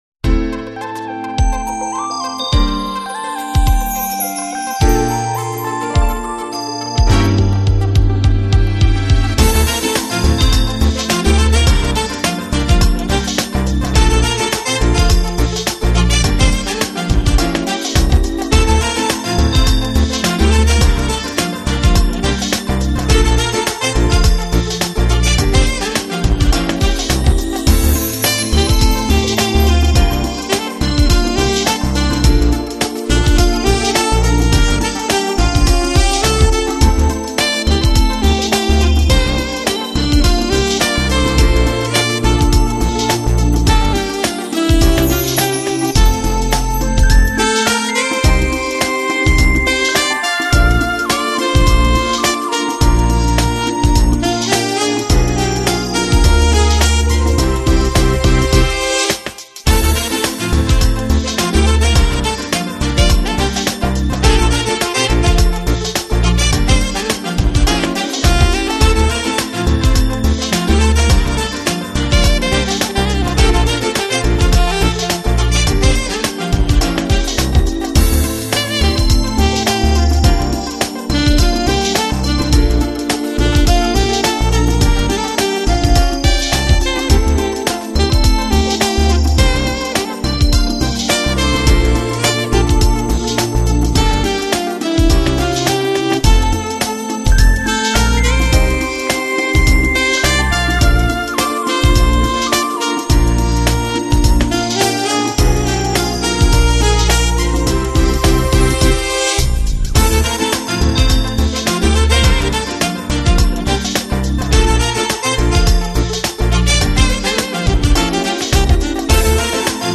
专辑流派：Jazz